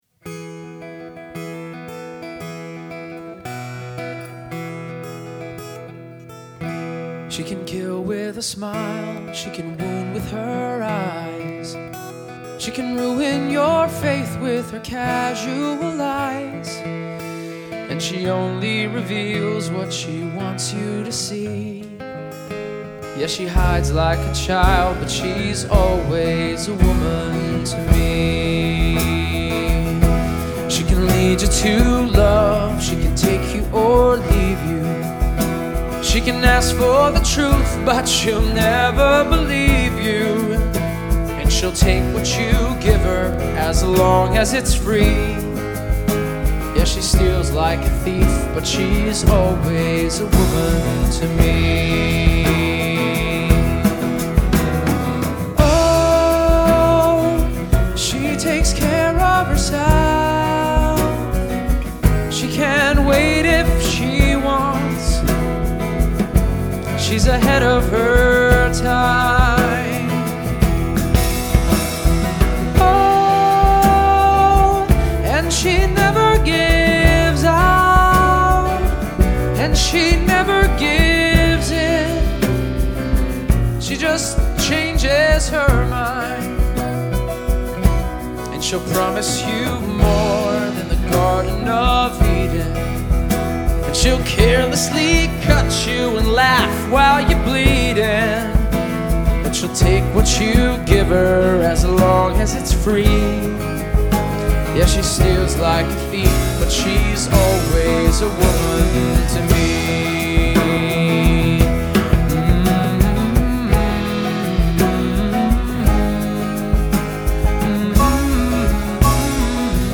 it’s like honey on the ears
it’s totally calming